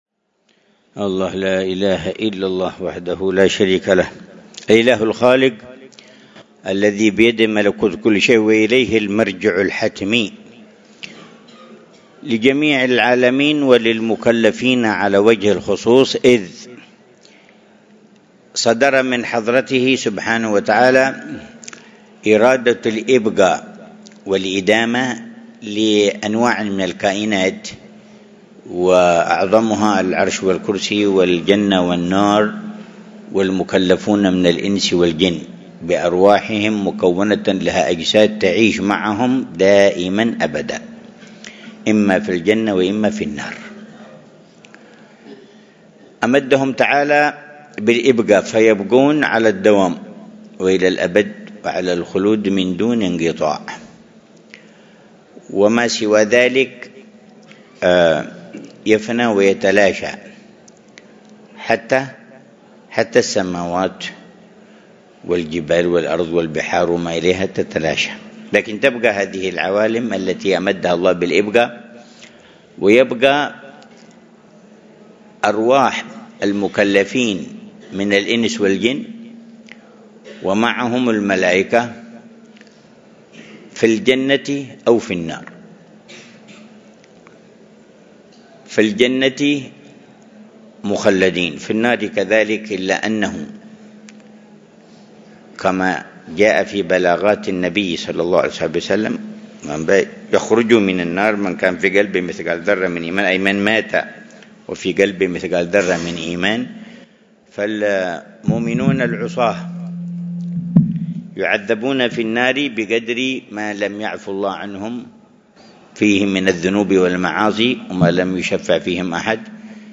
محاضرة العلامة الحبيب عمر بن حفيظ في الجلسة الشهرية مع طلاب ثانوية مدرسة دار المصطفى الأهلية بتريم، ضحى يوم الخميس 6 جمادى الآخرة 1447هـ .